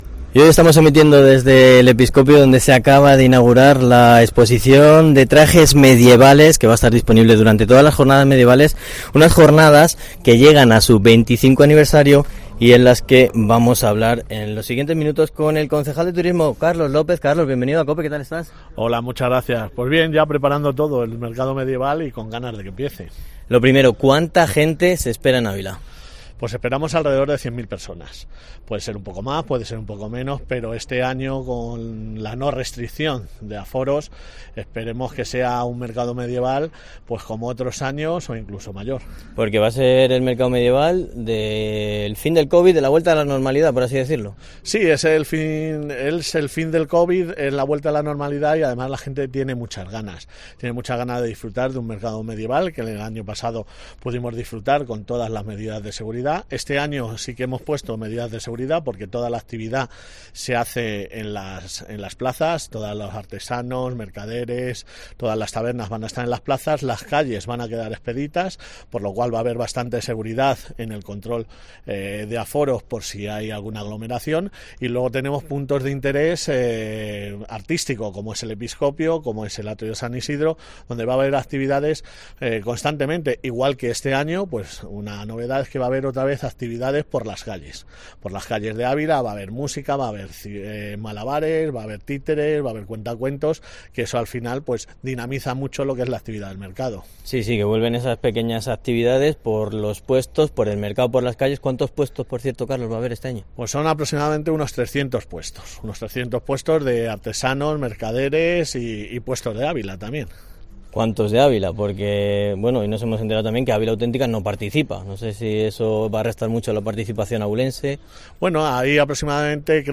Entrevista con el concejal de turismo Carlos López sobre el Mercado Medieval